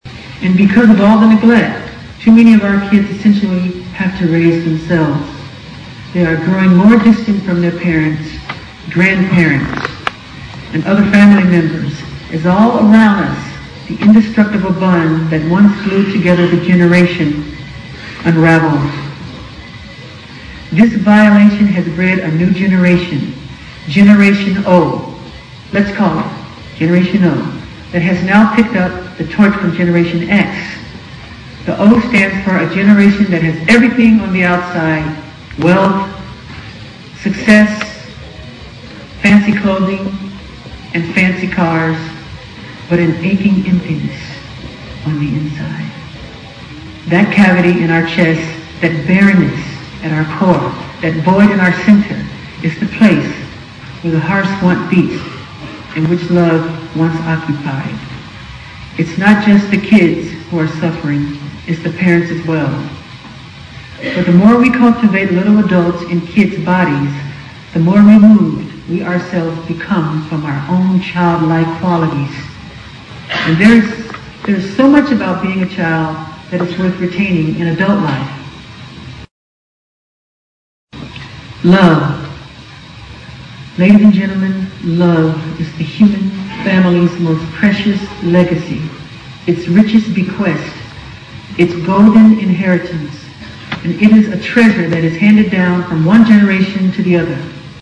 名校励志英语演讲 52:拯救世界 拯救儿童 听力文件下载—在线英语听力室
借音频听演讲，感受现场的气氛，聆听名人之声，感悟世界级人物送给大学毕业生的成功忠告。